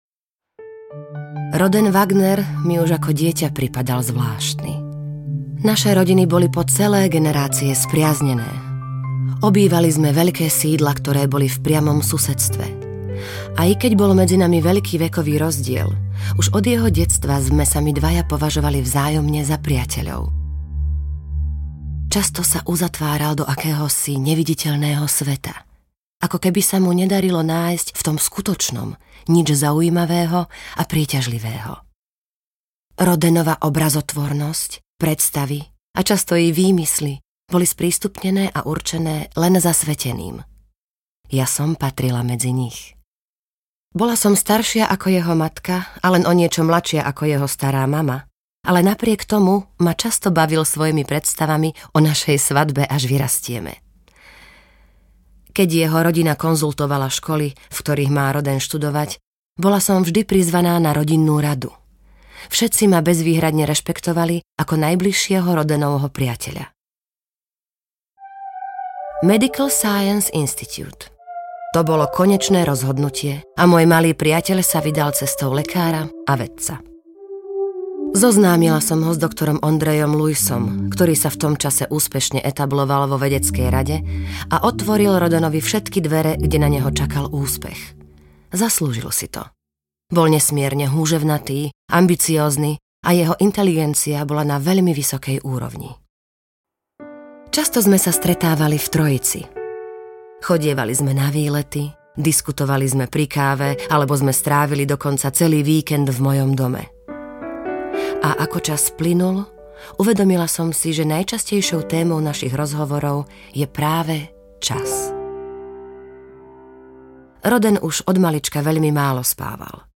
Roden24 audiokniha
Ukázka z knihy